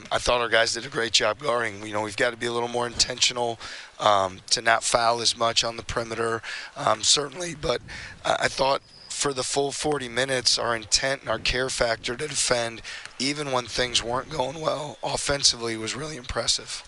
That's ISU coach T. J. Otzelberger.